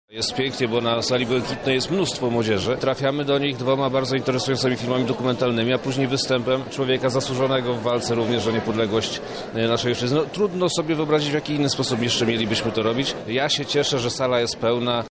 O tym w jakiej formie uczniowie mieli okazje poznać ich historię mówi Przemysław Czarnek- Wojewoda Lubelski.